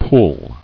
[pull]